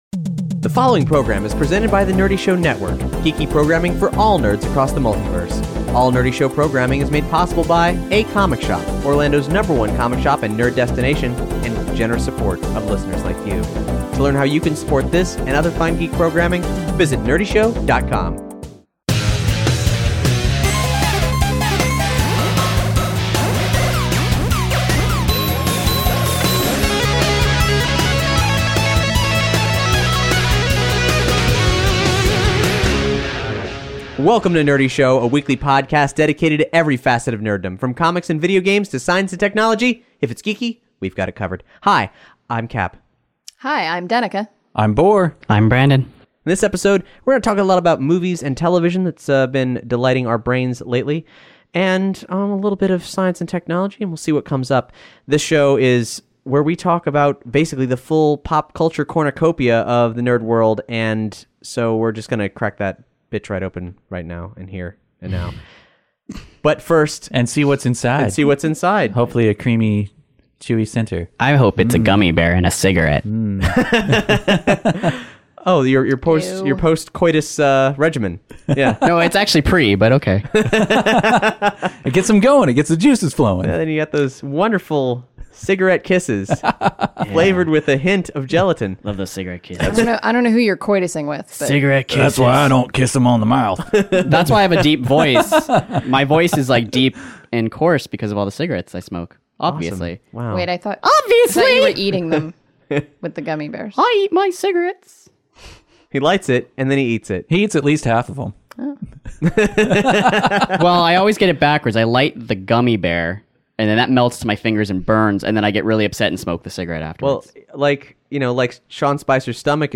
If you're jonesin' for some unfiltered geek conversation, well pal, you've come to the right podcast. Light up with Nerdy Show as we share some smokin' film, game, TV, science, and comics news and reviews.